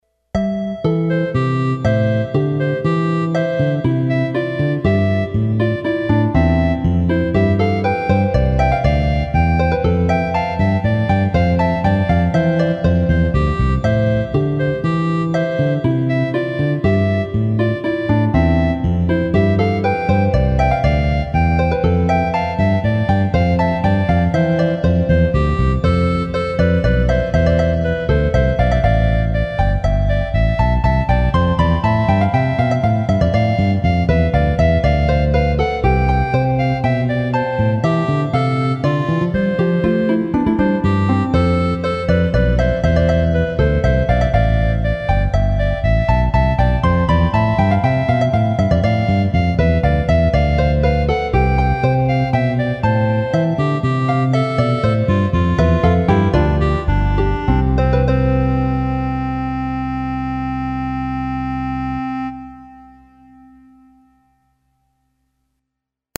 POLYPHONIC MUSIC